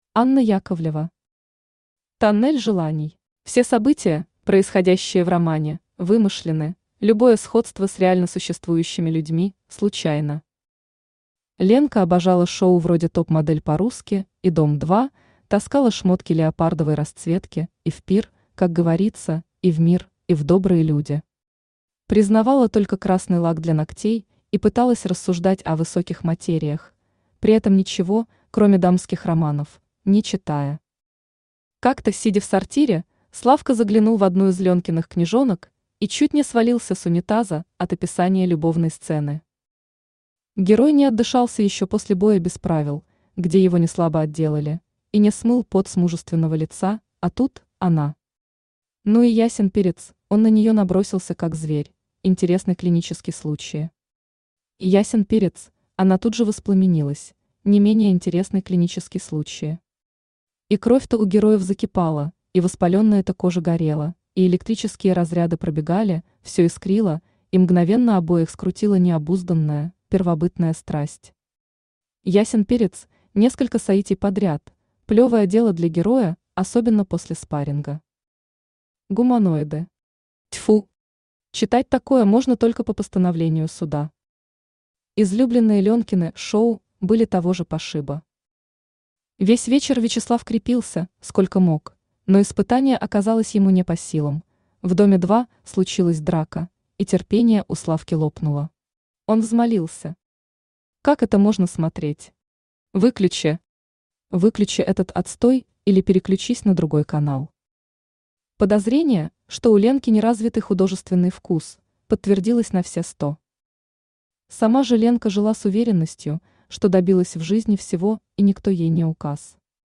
Аудиокнига Тоннель желаний | Библиотека аудиокниг
Aудиокнига Тоннель желаний Автор Анна Яковлевна Яковлева Читает аудиокнигу Авточтец ЛитРес.